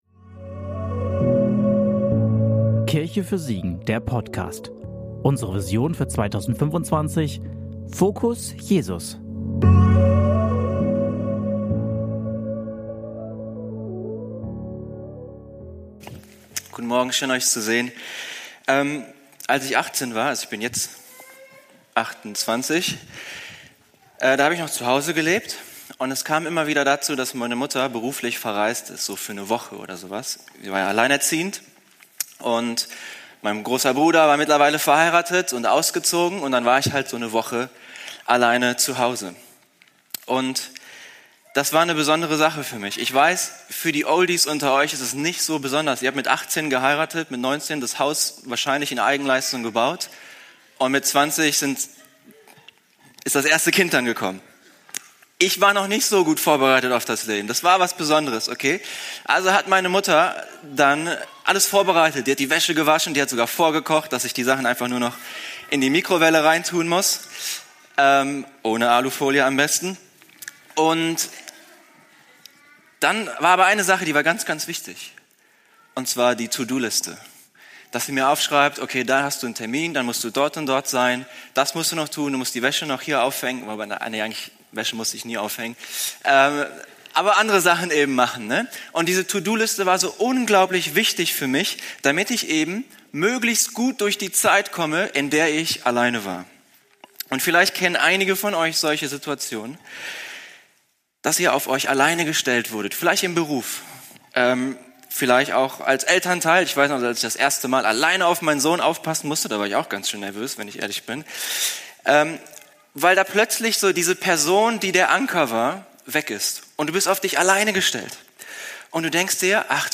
Predigt vom 28.09.2025 in der Kirche für Siegen